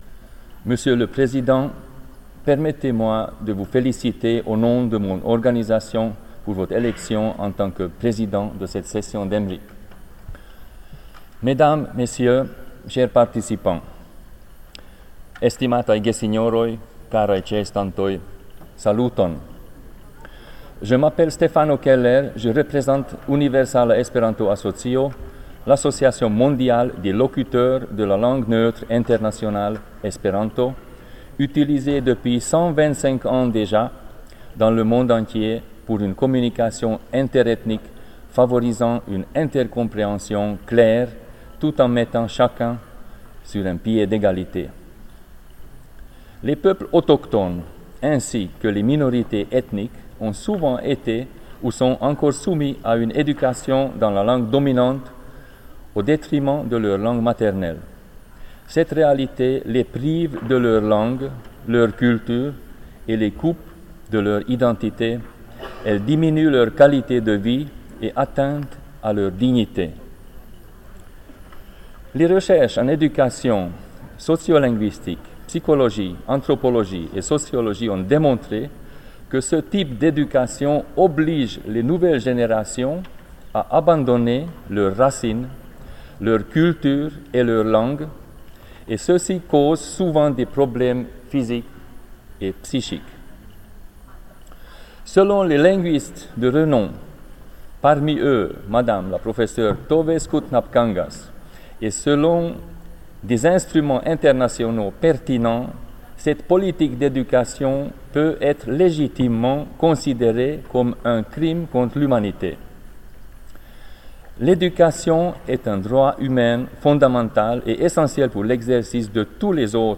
** (fr, franca) Déclaration de l'Association Universelle d'Espéranto (Universala Esperanto-Asocio, UEA) lors de la 5ème session du Mécanisme d'Experts sur les Droits des Peuples Autochtones (MEDPA-5), Palais des Nations, 9-13 juillet 2012 |